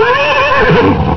horse05.wav